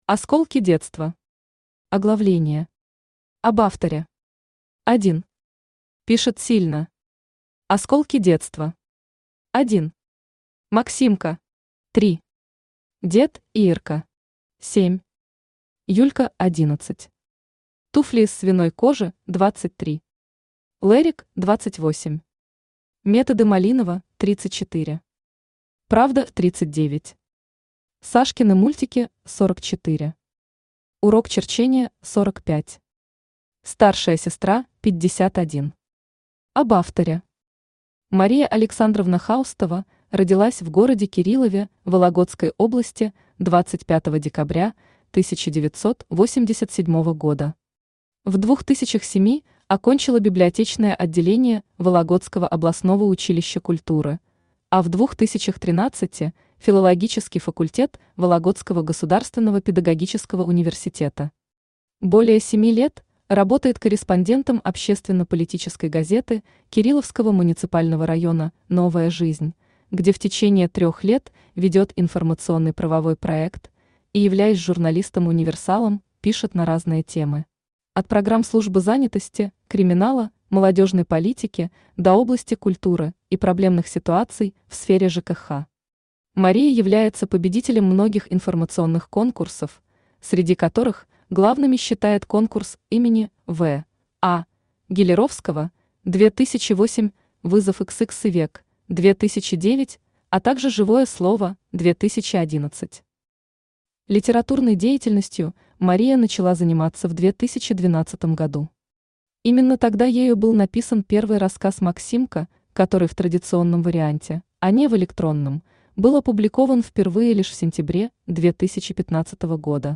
Aудиокнига Осколки детства Автор Мария Александровна Хаустова Читает аудиокнигу Авточтец ЛитРес.